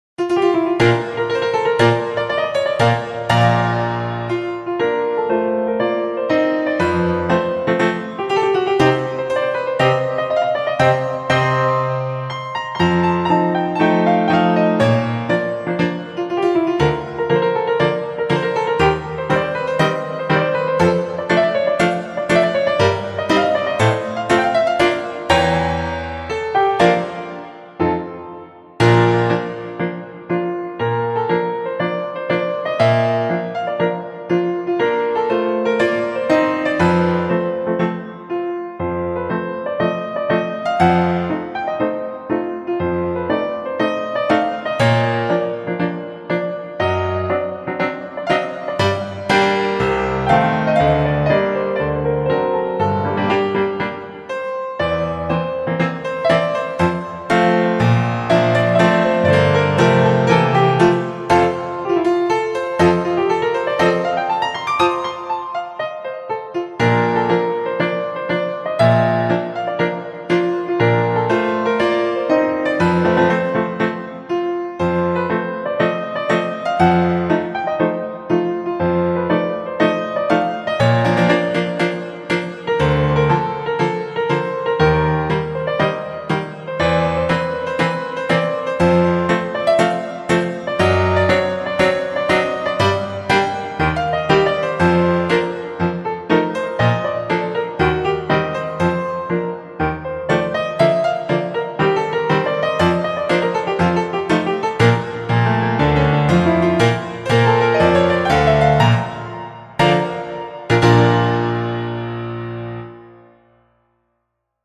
Бразильский гимн на фортепиано